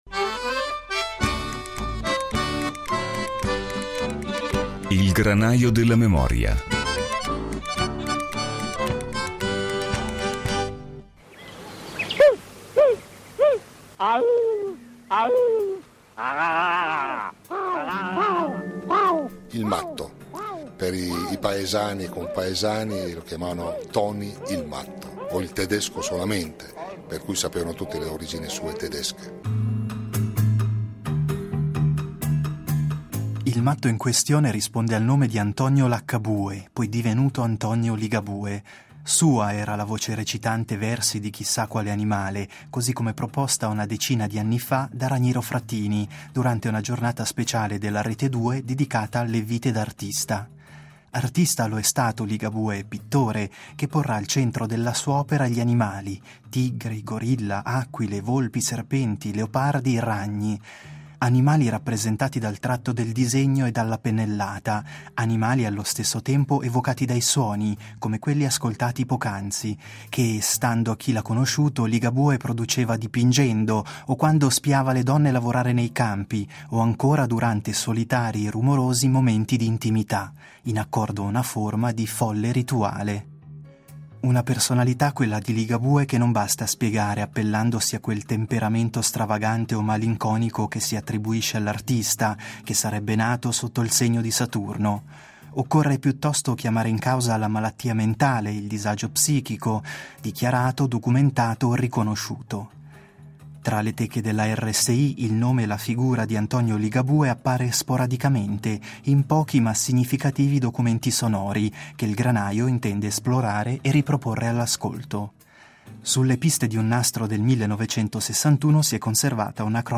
Qualcuna delle voci che hanno conosciuto il pittore, l’uomo e l’artista sono conservate tra le teche della RSI, disseminate in alcuni documenti sonori che - soprattutto verso la fine degli anni Sessanta - si erano interessati alla figura del matto Laccabue .
Il Granaio della memoria è andato alla ricerca di queste voci, così da tracciare un ritratto radiofonico di Antonio Ligabue, ovvero di un artista dalla complessa personalità e di un pittore che immaginava l’entroterra emiliano come popolato da bestie feroci, dipingendole. Ne nasce un percorso d’archivio eclettico, che sfiora i margini dell’arte e che non mancherà di fare un accenno alla corrente alla quale Antonio Ligabue apparteneva, forse senza mai averlo davvero saputo: l’art brut .